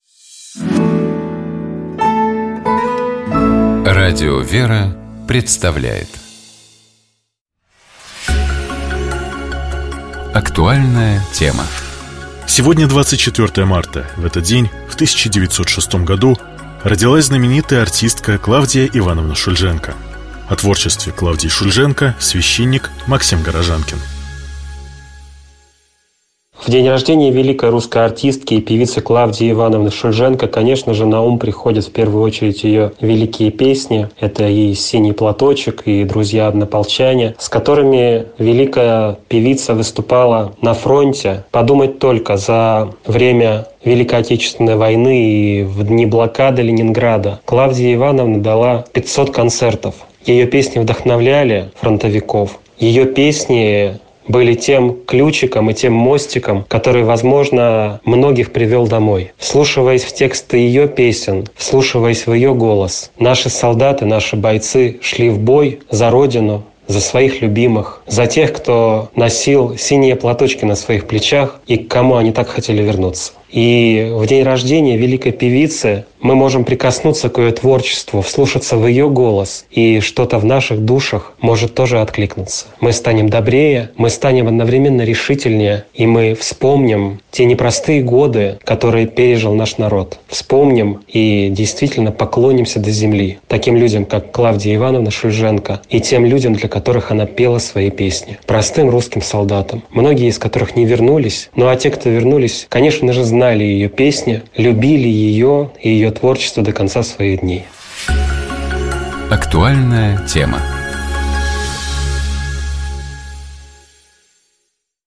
О творчестве Клавдии Шульженко, — священник